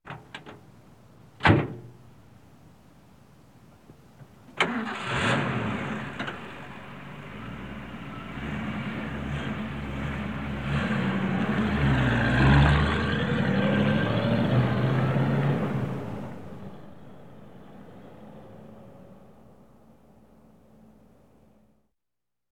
Звуки фуры
Запуск пикапа и звук его отъезда